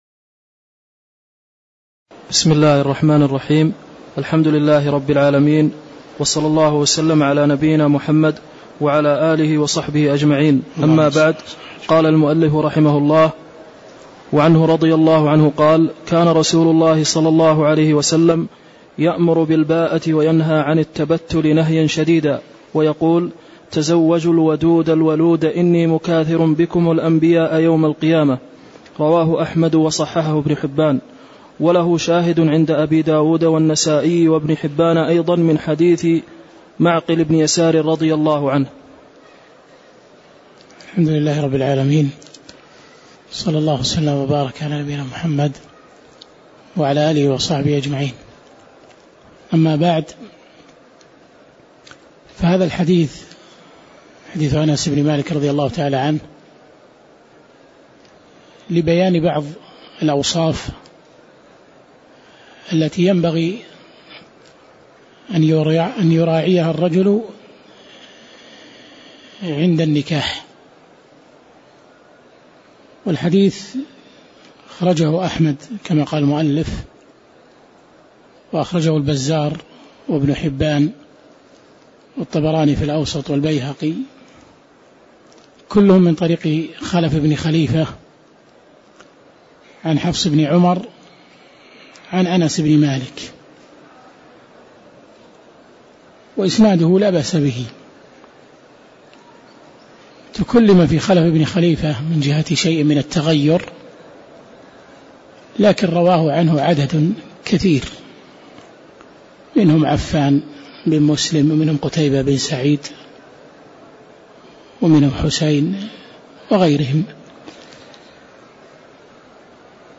تاريخ النشر ١٢ صفر ١٤٣٧ هـ المكان: المسجد النبوي الشيخ